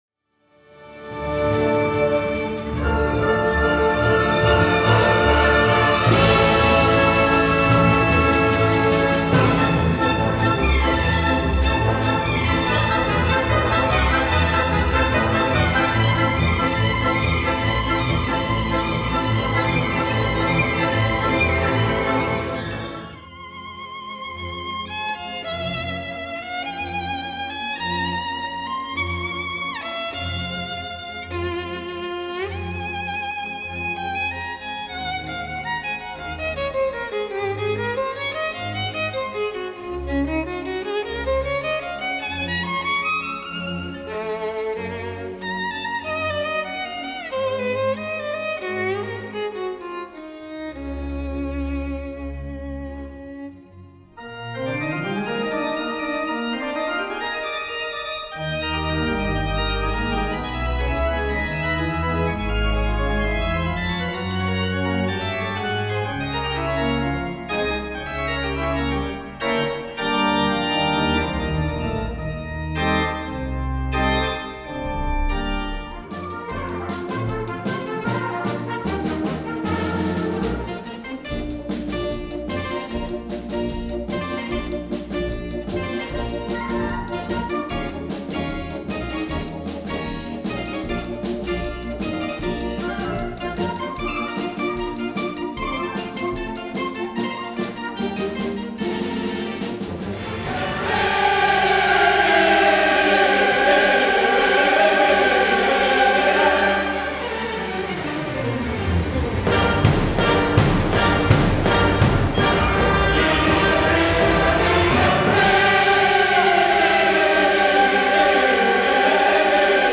(Konzertaufnahme, Tonaufnahme, Tonaufzeichnung)
Audio-Demos Verschiedene Live-Recording Beispiele Kleiner Chor + Ensemble (ref.
Kirche Brugg) Cello + Sinfonieorch.
Marimba Solo
Blasorchester
Chor + Orchester